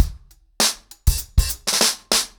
DrumkitRavage-100BPM_1.3.wav